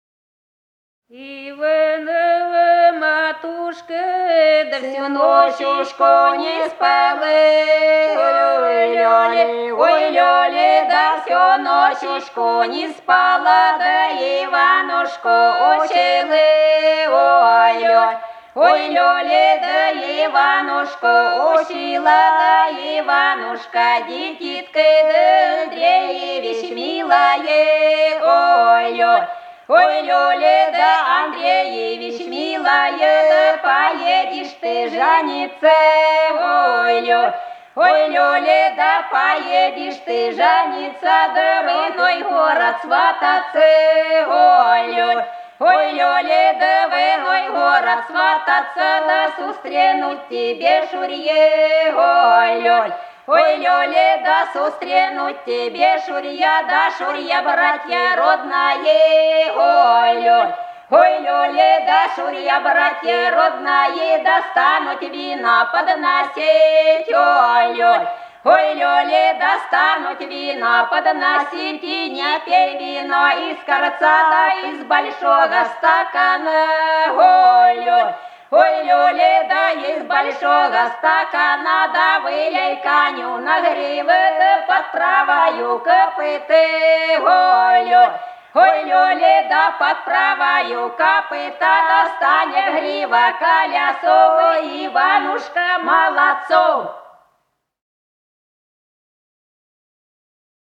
Голоса уходящего века (село Фощеватово) Иванова матушка всю ночушку не спала (поют жениху)
15._Иванова_матушка_всю_ночушку_не_спала_(поют_жениху).mp3